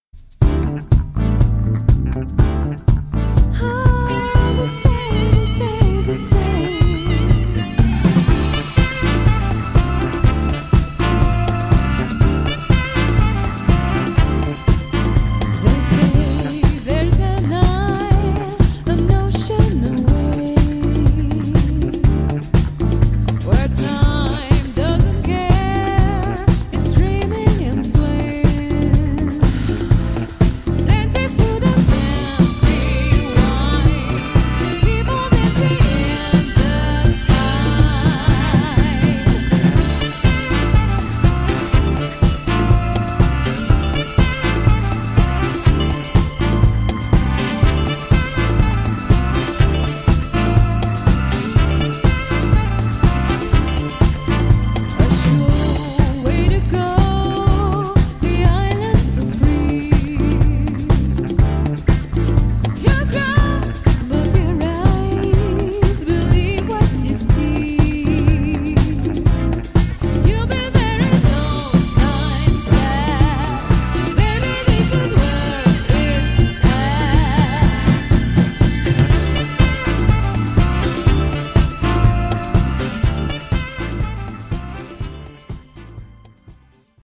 ジャンル(スタイル) JAZZ / FUNK / DISCO / CROSSOVER